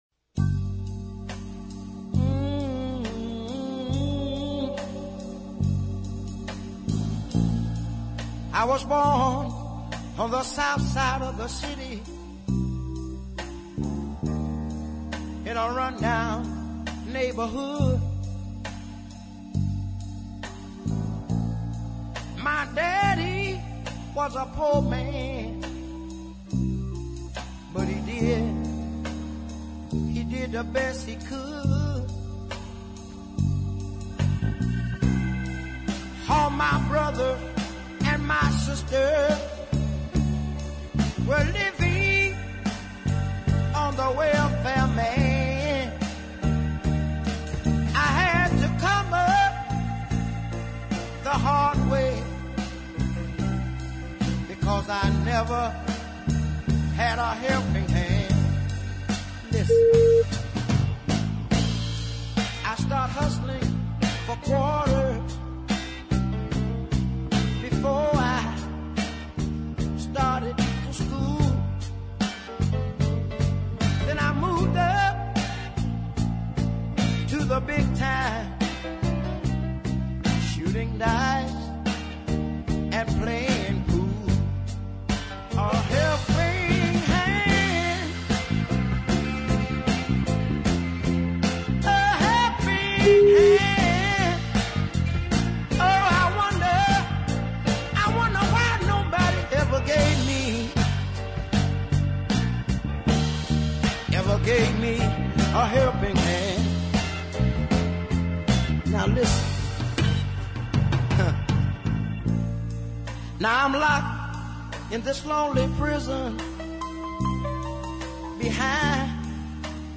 prime southern soul
hoarse tone and easy delivery
down beat